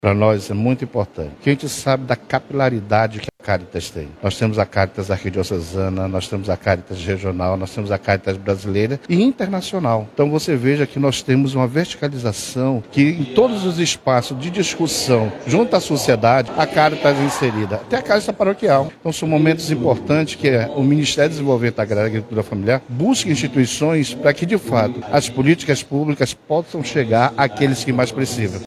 O seminário buscou promover uma reflexão sobre as melhores formas de apoiar e dar sustentabilidade às práticas de economia solidária que já são realizadas pelos grupos em Manaus, explica o Superintendente do Ministério de Desenvolvimento Agrário e Agricultura no Amazonas, Marcos Brito.
SONORA-1-MARCOS-BRITO.mp3